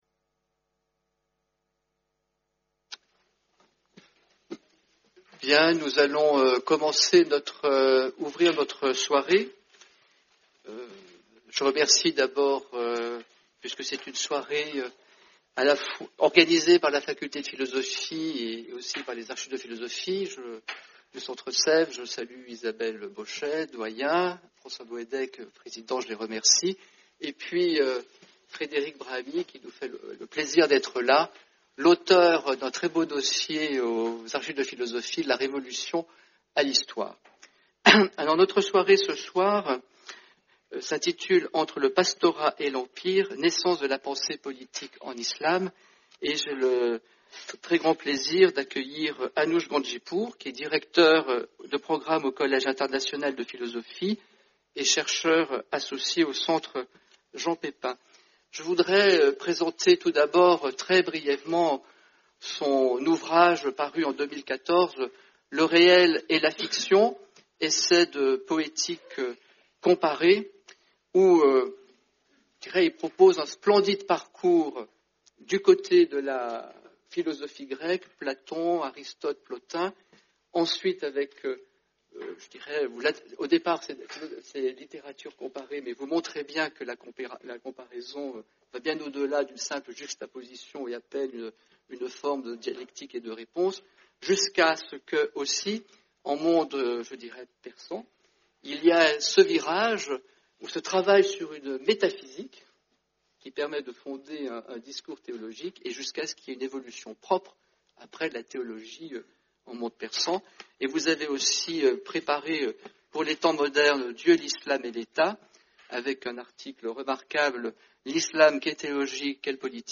Soirée organisée par la revue Archives de Philosophie